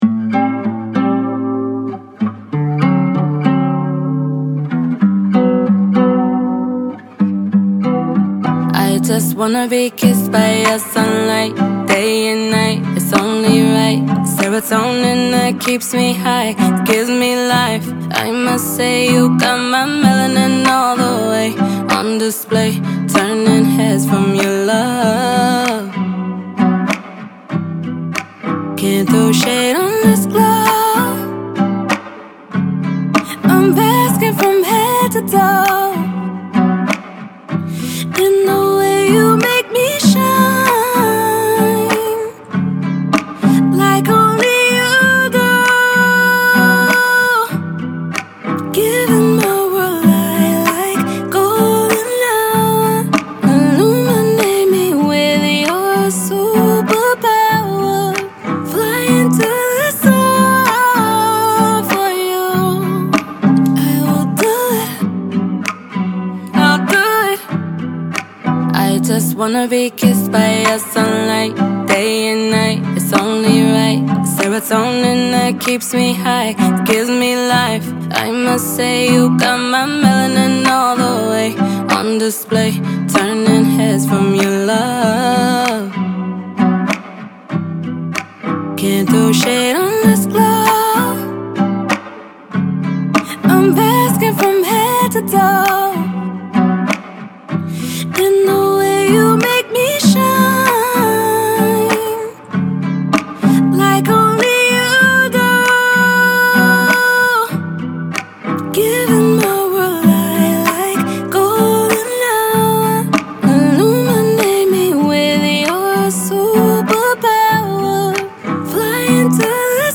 R&B, Soul, Acoustic, Pop
G Major